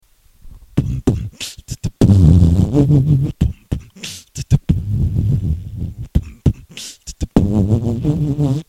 Выкладываем видео / аудио с битбоксом
1)Bm Bm kch tt Bwww www ( низкая тональность)
Bm Bm kch tt Bwww www ( высокая тональность)
обидно в самом то деле) просто я на ноутовский микрофон записую)